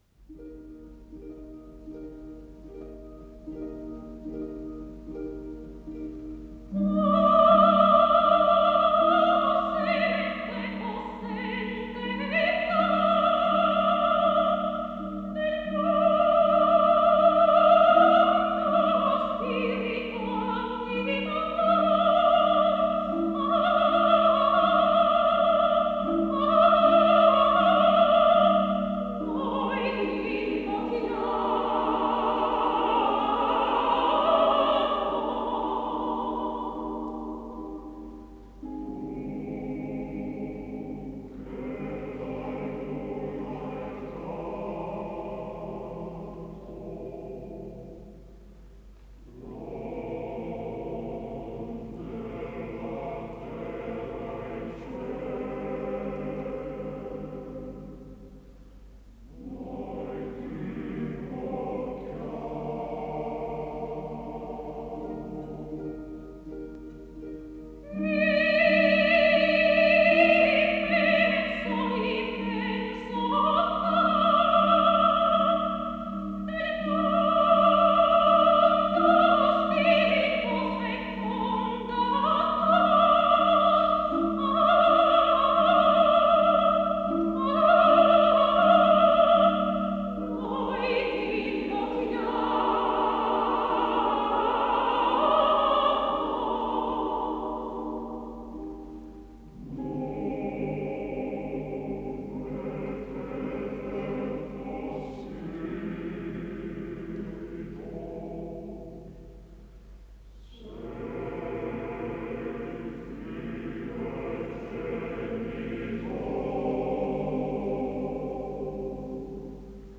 Исполнитель: Солисты, хор и оркестр Софийской народной оперы